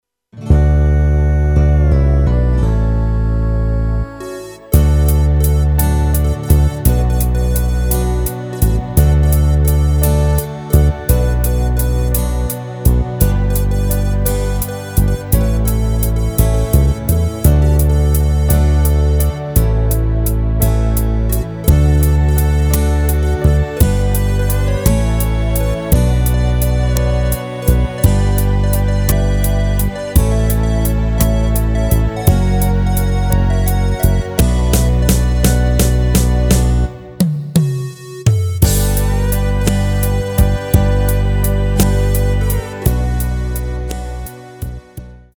Rubrika: Folk, Country
Karaoke
HUDEBNÍ PODKLADY V AUDIO A VIDEO SOUBORECH